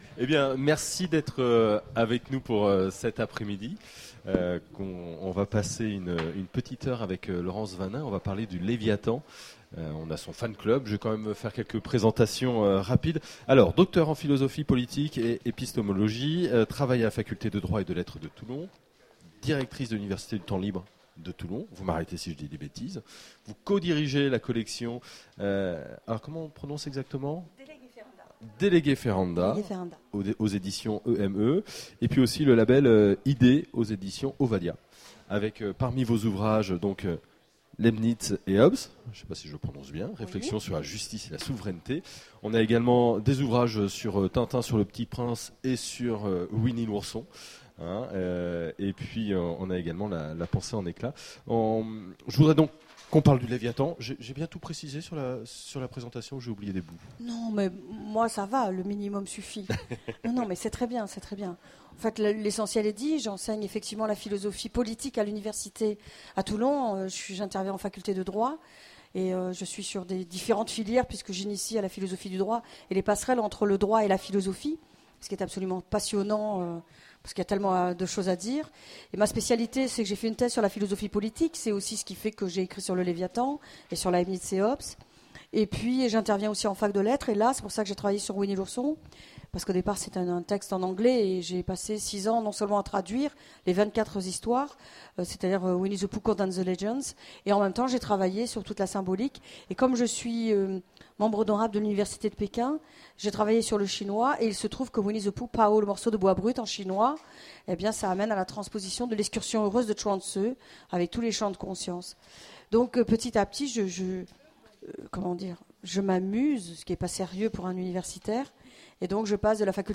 Imaginales 2015 : Conférence Le Léviathan